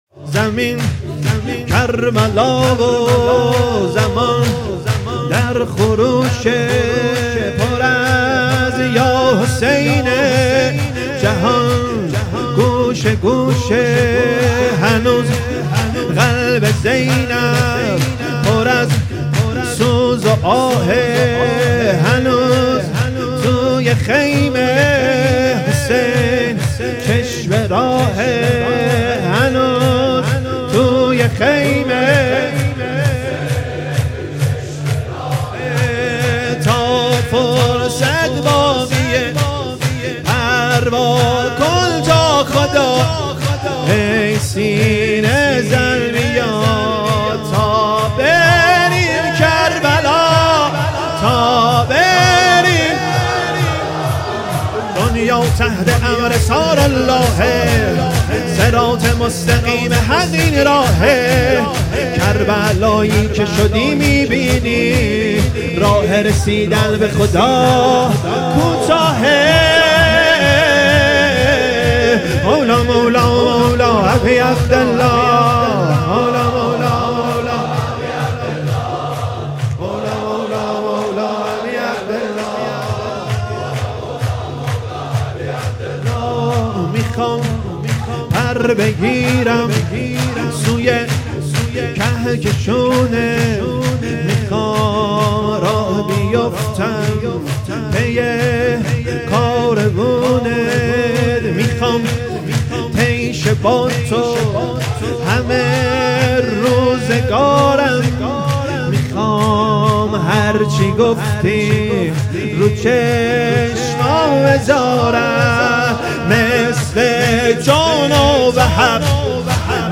شور | زمین کربلا
سینه زنی شور
جلسه هفتگی محبین عقیلة العرب(س) تبریز